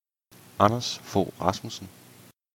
Anders Fogh Rasmussen S.K. (Danish: [ˈɑnɐs ˈfɔwˀ ˈʁɑsmusn̩]